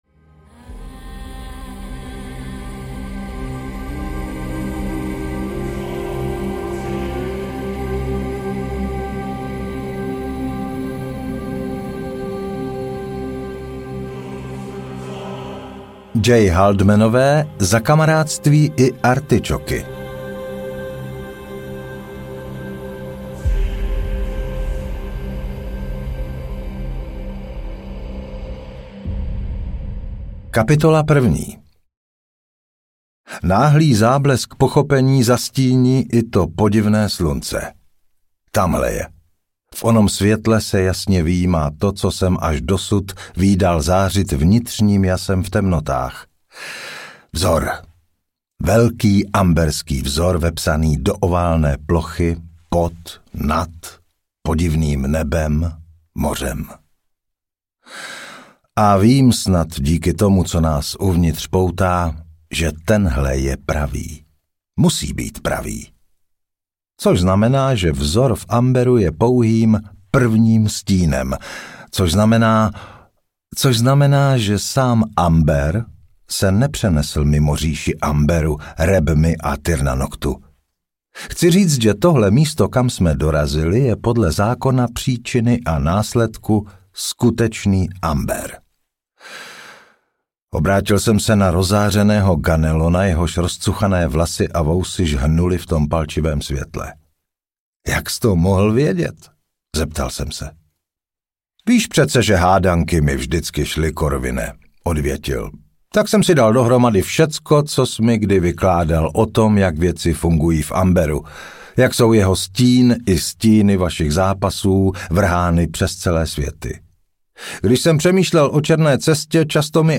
Ukázka z knihy
amber-4-paze-oberonova-audiokniha